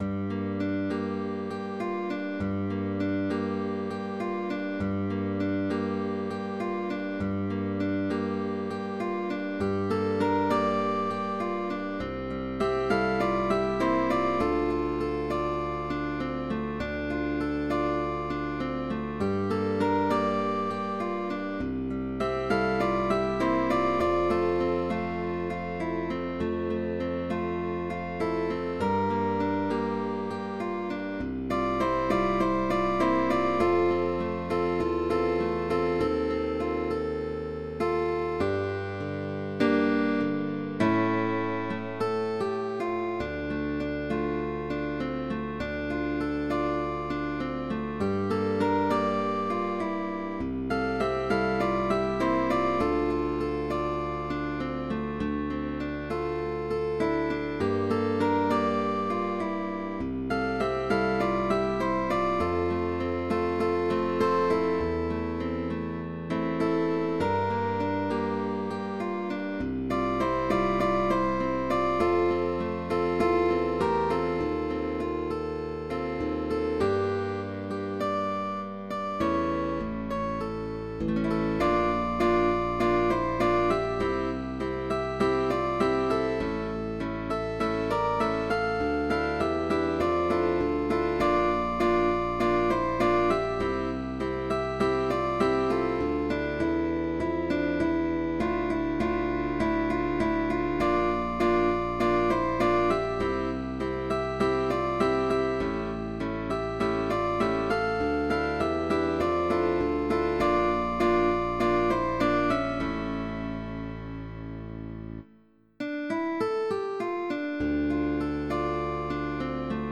with optional bass.
High positions, Chords, Arpeggios,…
Pop music